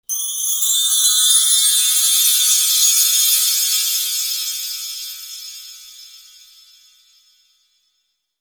Звон/Колокол и прочее
Звук сказочного колокольчика